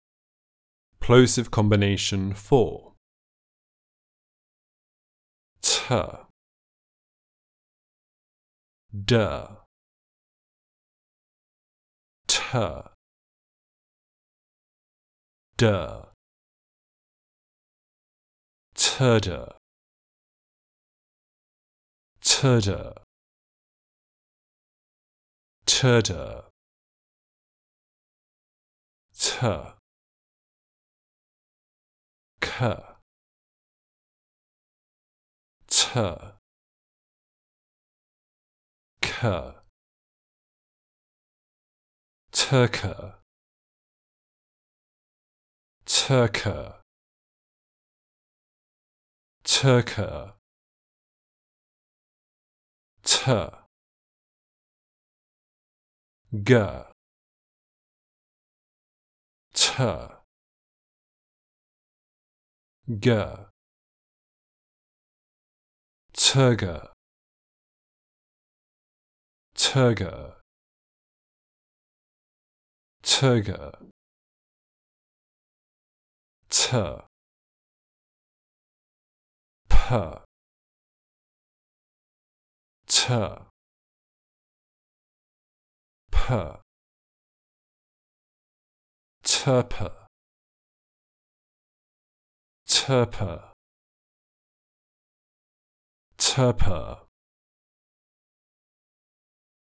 The Basic Building Blocks of Speech - Level 02 - British English Pronunciation RP Online Courses
Plosive combination 04
04_combination_plosive_04.mp3